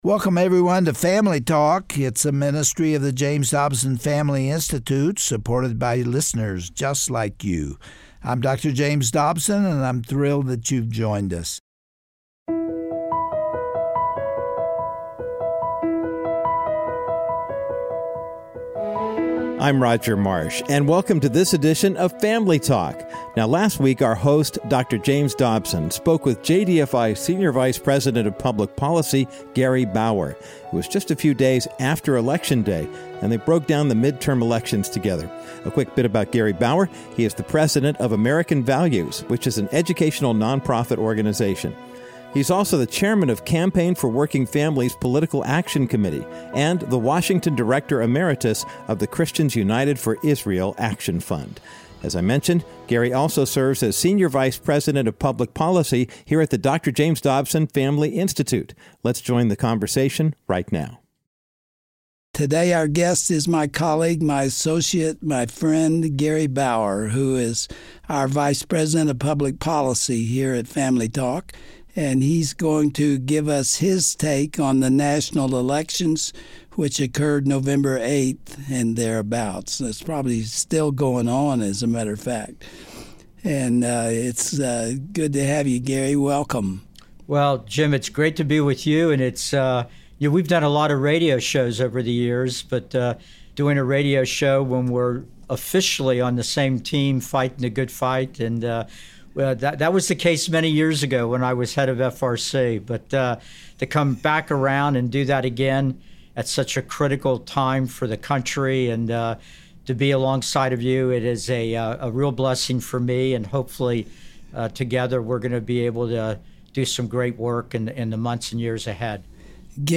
On today’s edition of Family Talk, Dr. James Dobson and Gary Bauer, vice president of public policy at the JDFI, discuss the outcome of the recent election and what the future holds for our country.